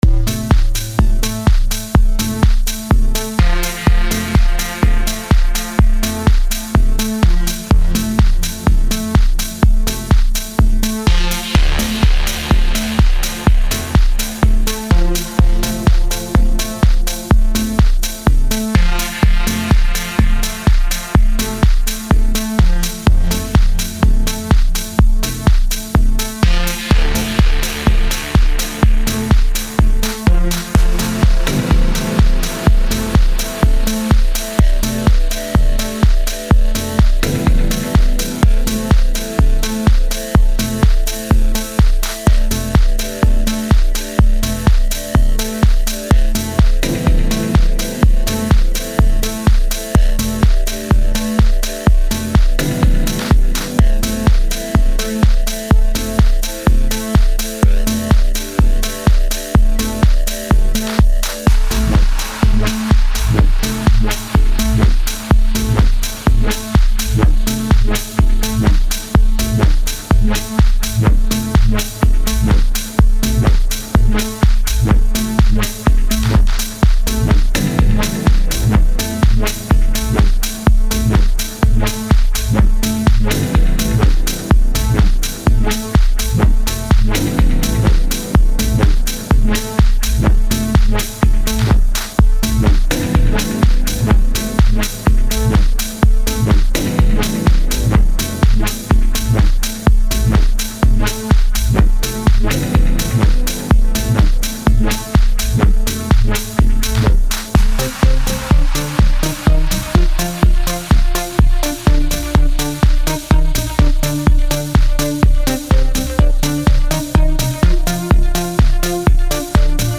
Type: Samples
Deep House Melodic Techno Minimal
• 5 Drum Loops
• 16 Synths